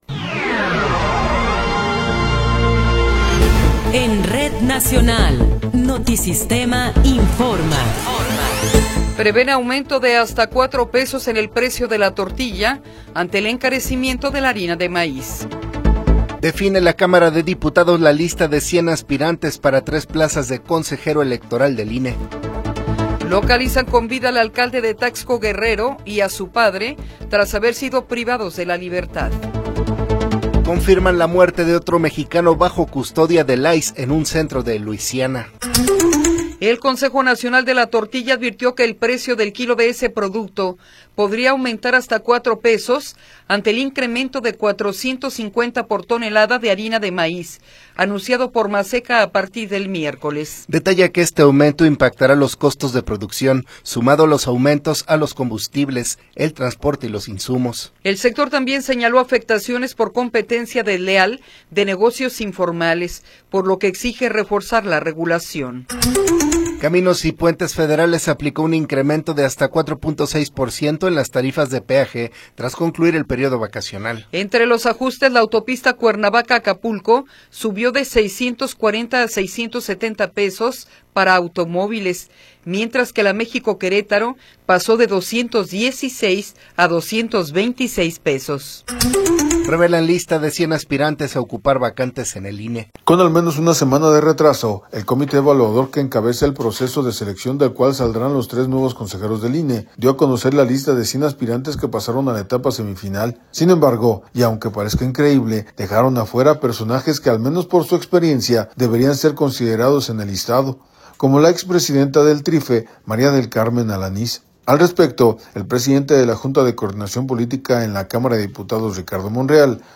Noticiero 8 hrs. – 14 de Abril de 2026
Resumen informativo Notisistema, la mejor y más completa información cada hora en la hora.